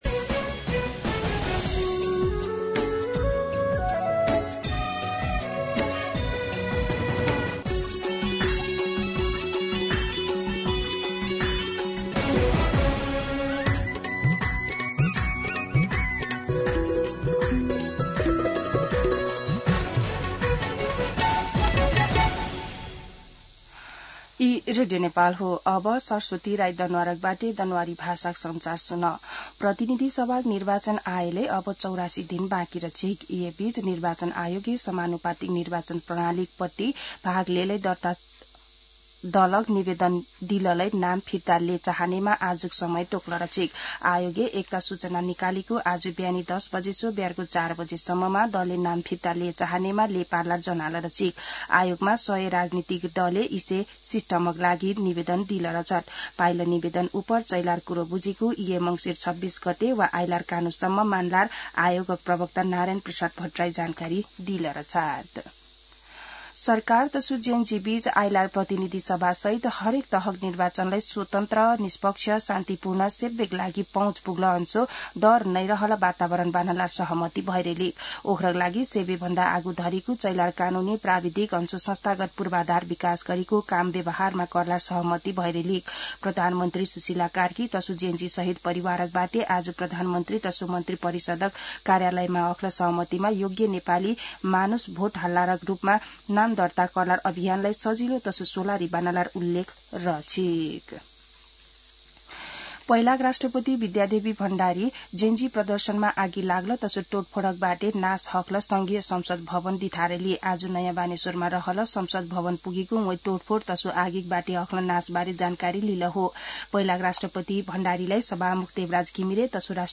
दनुवार भाषामा समाचार : २५ मंसिर , २०८२
Danuwar-News-8-25.mp3